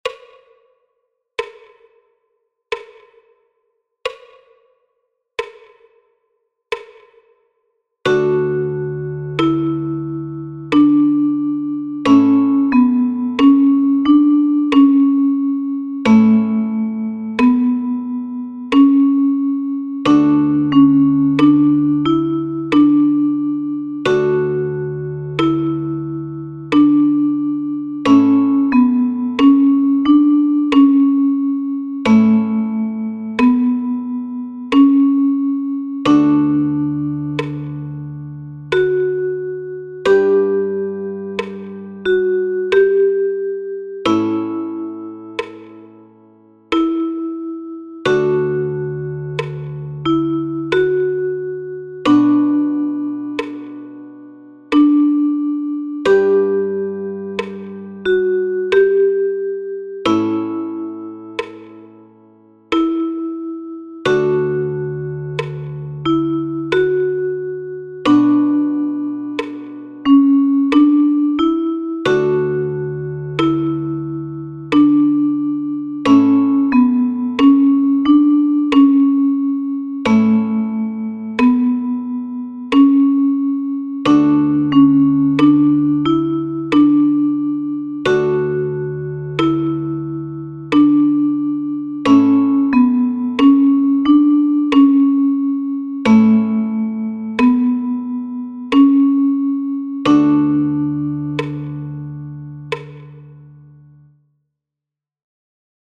Liederbuch (in Deutsch & English) mit 35 Liedern aus dem Mittelalter und der Renaissance für die Kalimba mit 17 Stimmzungen.